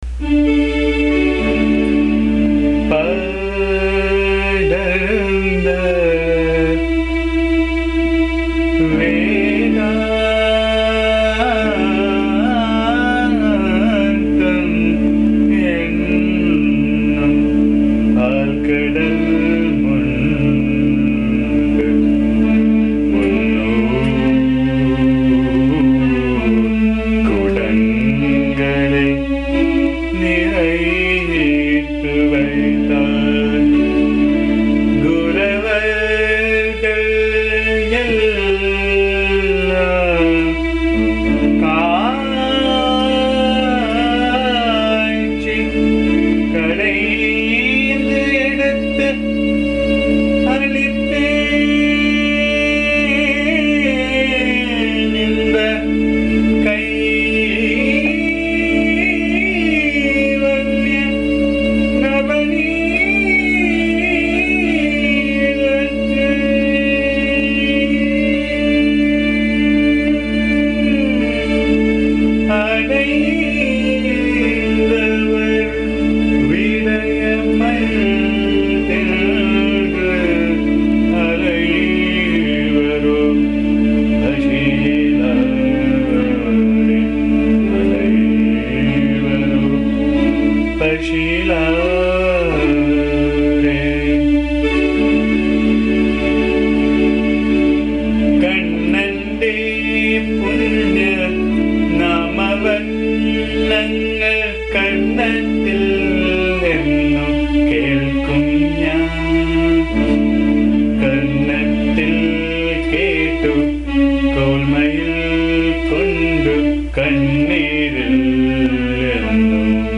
Any amount of trying to translate or explain this song will only spoil the beautiful import of the song written in malayalam and set to the apt Desh Raga.
The song sung in my voice can be found here.
AMMA's bhajan song